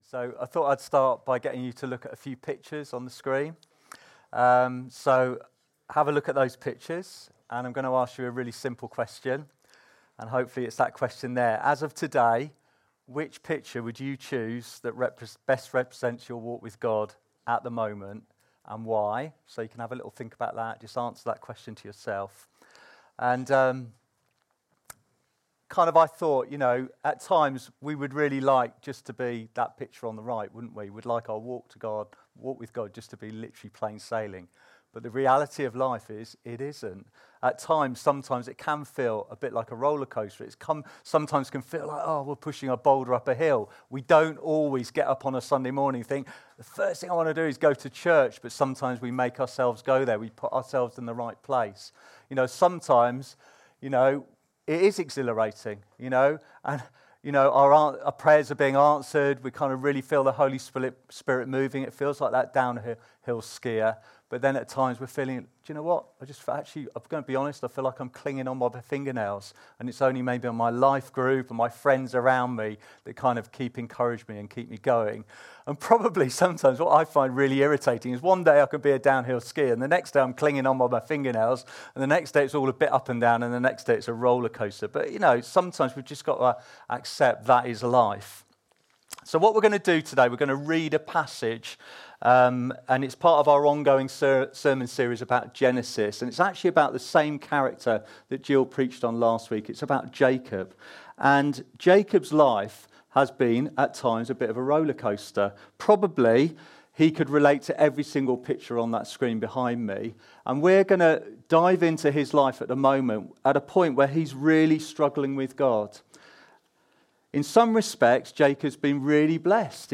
Download Wrestling With God | Sermons at Trinity Church